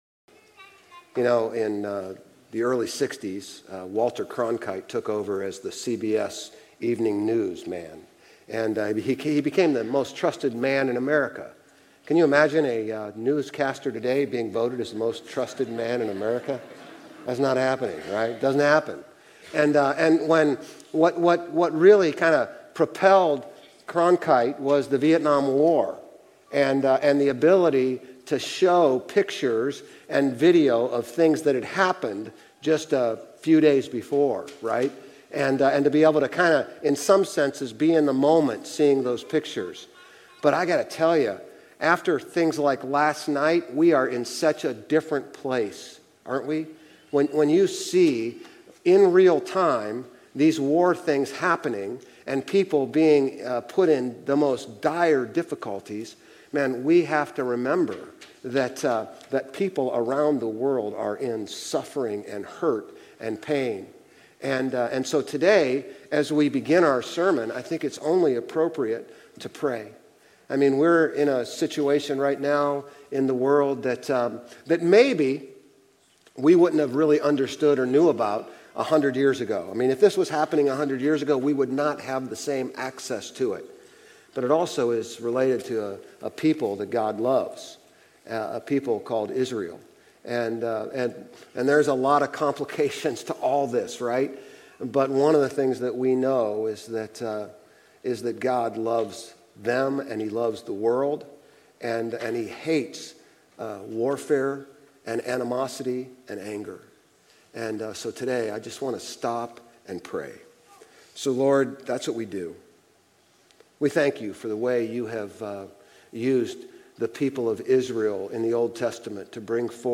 Grace Community Church Old Jacksonville Campus Sermons Galatians 2:1-14 Apr 15 2024 | 00:31:47 1x 00:00 / 00:31:47 Subscribe Share RSS Feed Share Link Embed